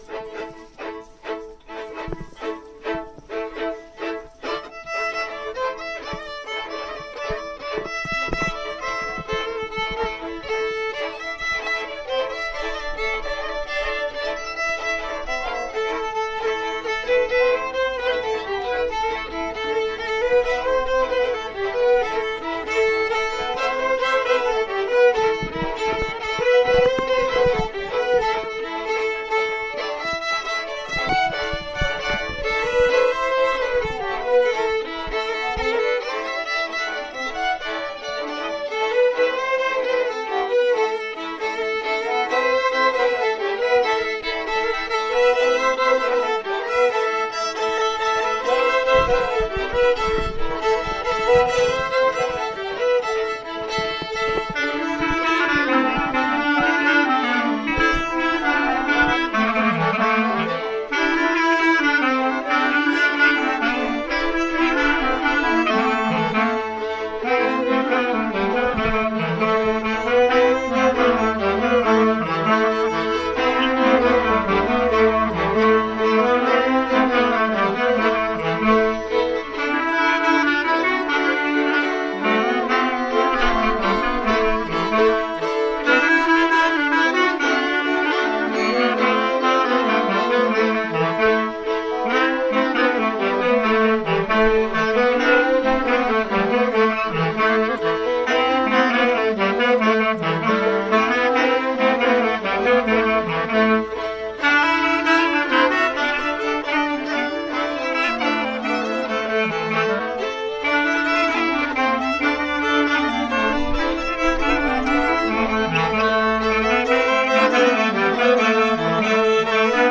04_7_temps_clarinette_violon.mp3